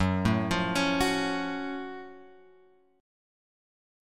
F#mM7 chord {2 4 3 2 2 5} chord